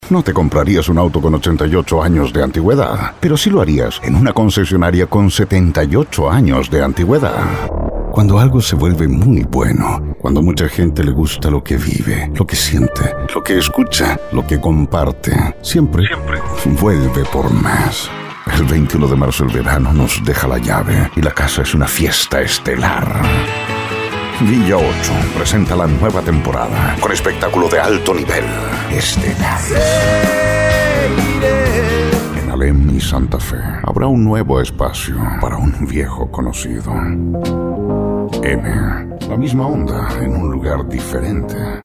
Neutro